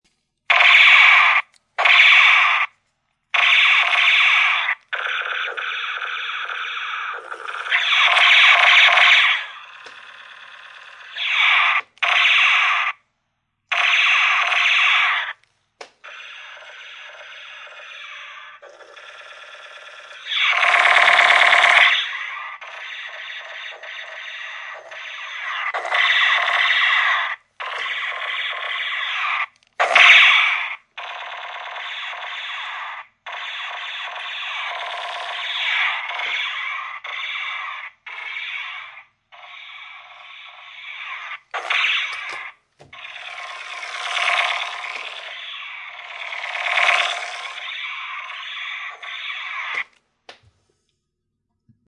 Download Toy Gun sound effect for free.
Toy Gun